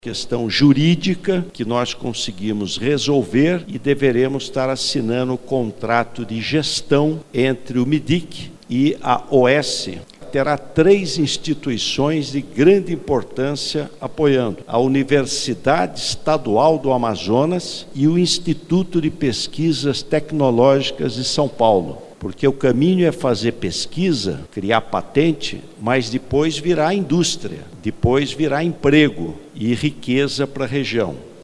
As falas ocorreram nessa sexta-feira, 24, durante a primeira Reunião Ordinária do Conselho de Administração da Suframa (CAS) e a primeira presidida pelo Ministério.